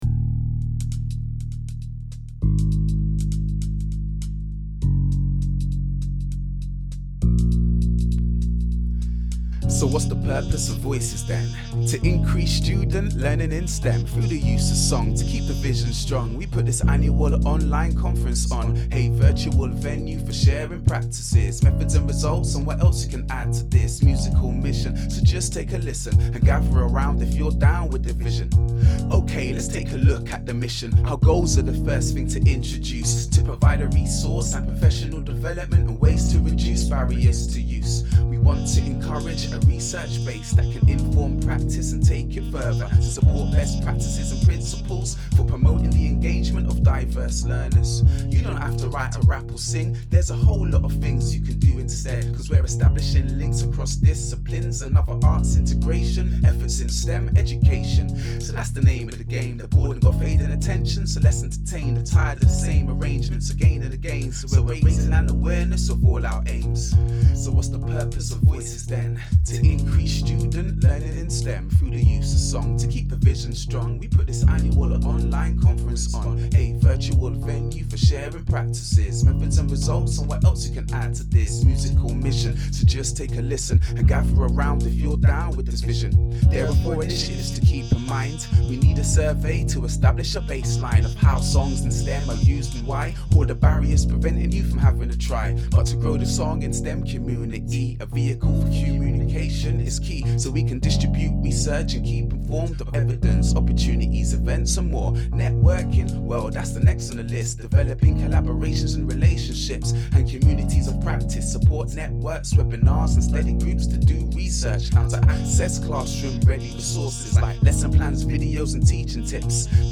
live rap
Materials VOICES 2019 - (W)rap Up - Lyrics.pdf VOICES 2019 - (W)rap Up.mp3 Tags Rap View the discussion thread.